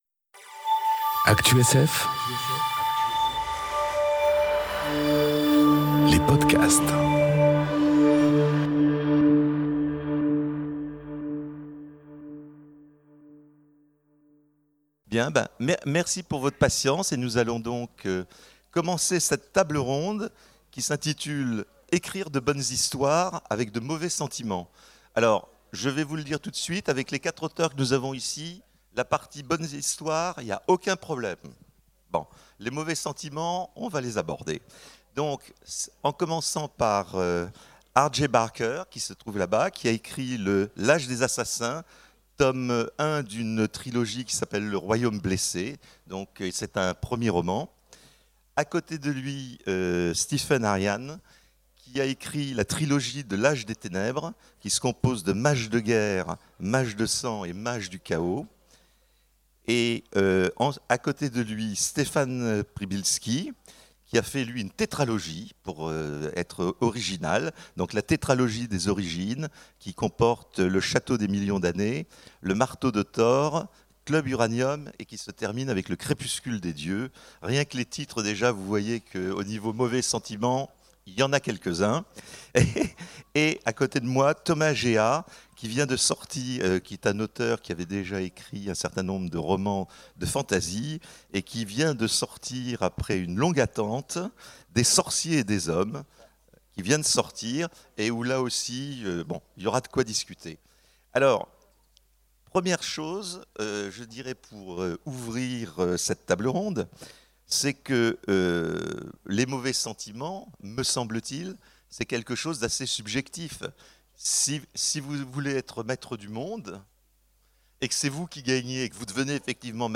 Conférence Écrire de bonnes histoires... Avec de mauvais sentiments ? enregistrée aux Imaginales 2018